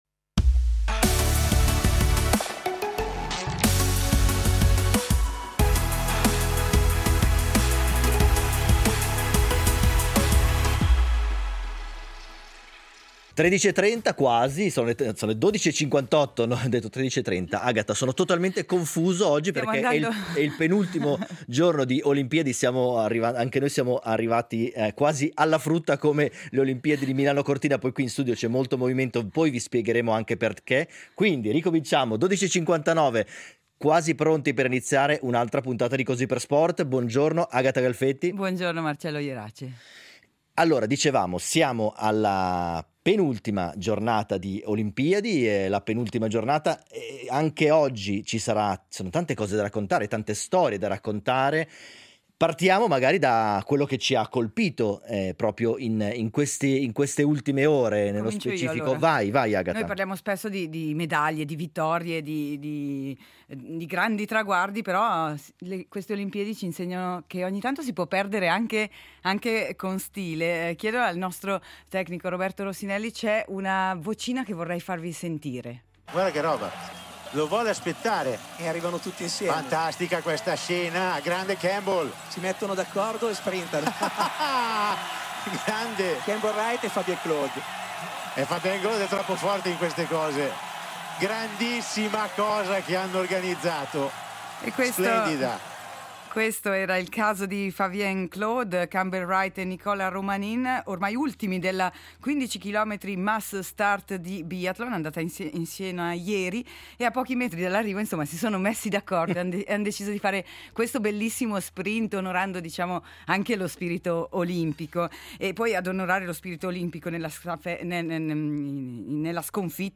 Tornano le Olimpiadi e torna Così per sport per raccontarvi minuto per minuto i Giochi di Milano-Cortina. I risultati, il medagliere, le voci degli inviati e le storie degli ospiti che hanno vissuto sulla propria pelle la rassegna a cinque cerchi, ma anche i dietro le quinte e le curiosità delle varie discipline per immergerci tutti insieme – sportivi e meno – nello spirito olimpico.